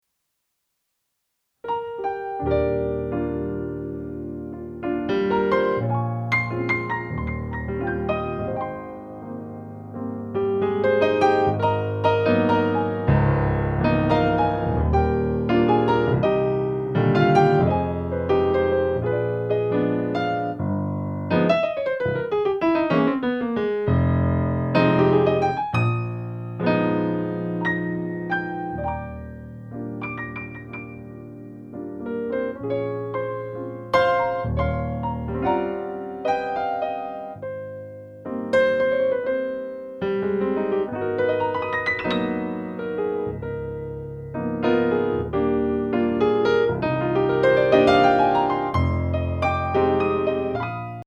Background/static Noise occurring when recording an electronic keyboard
I guess there is still a LITTLE bit of that white noise even with the new Audio interface, but I would say it's a HUGE improvement from before.